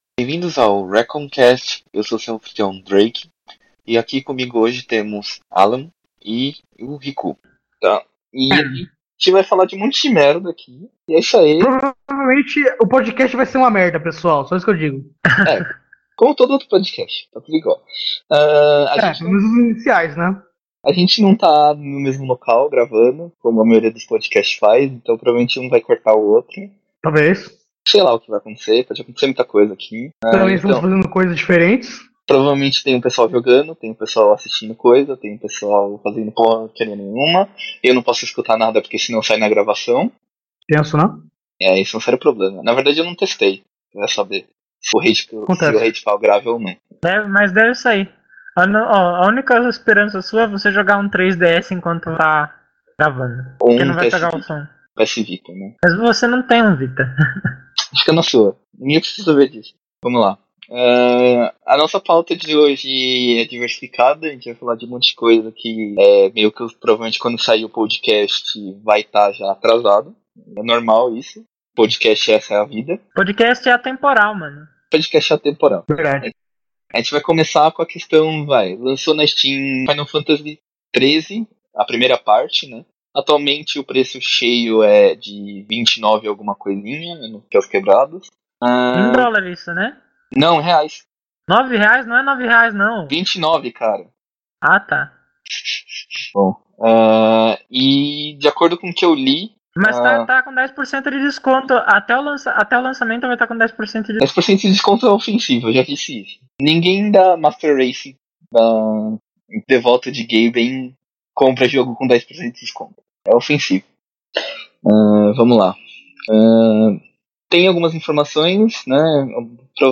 Chegamos ao primeiro RaccoonCast, espero que vocês não se importem, pois ainda estamos definido algumas questões de qualidade e formato. Além do que o pessoal ainda se corta bastante, mas é o que temos para hoje, tem que começar alguma hora.